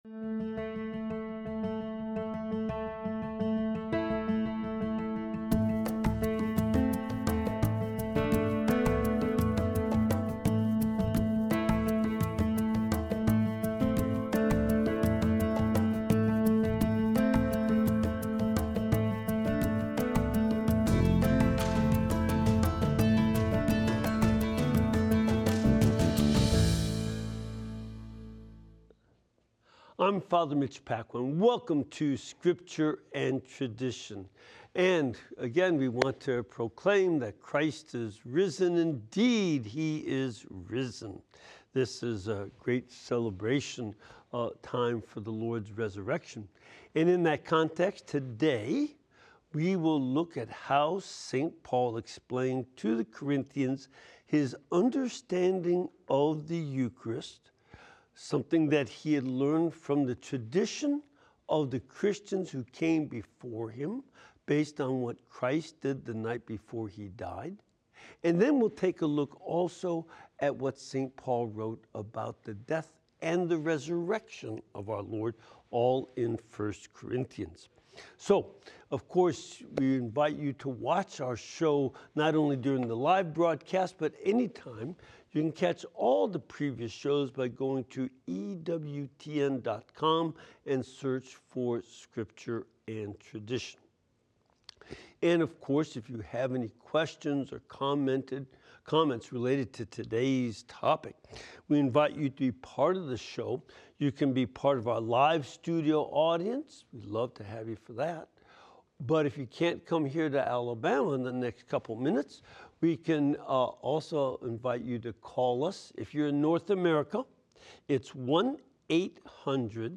analyzes Church Traditions and Teachings in light of Sacred Scripture during this weekly live program.